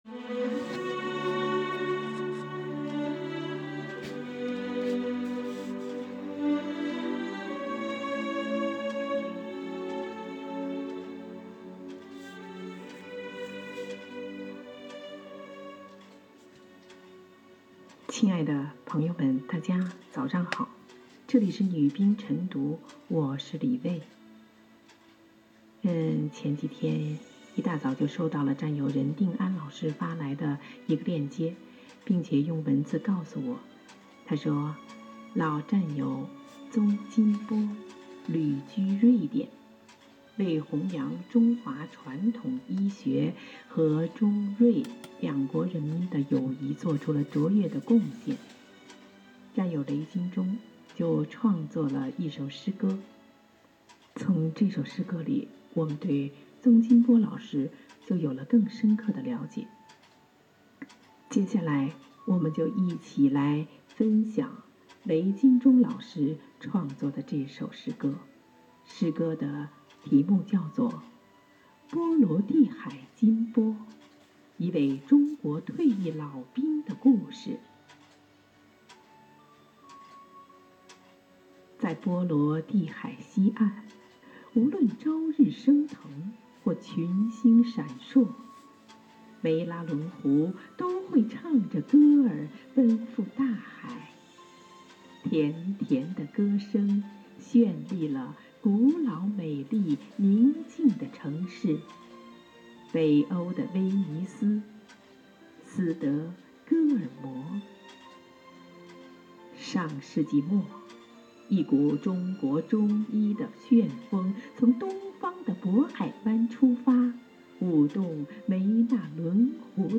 有几十位当代知名的诗人、战友诵读